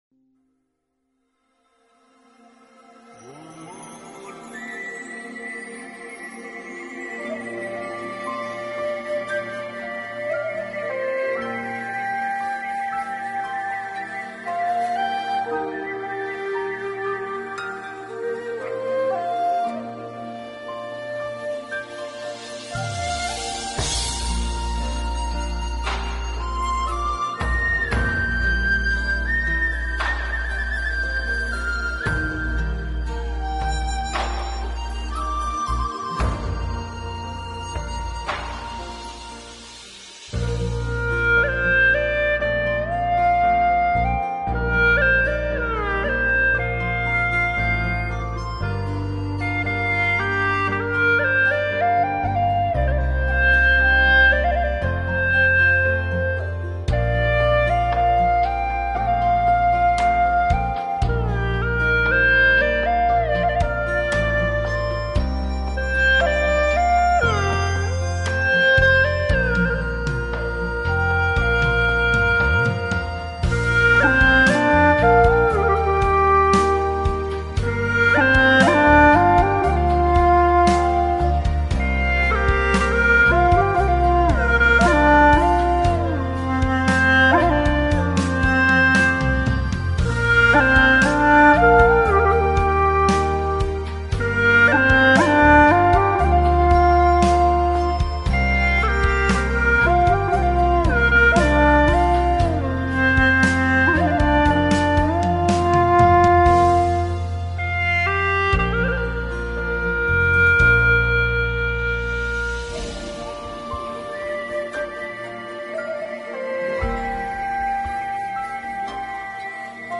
调式 : 降B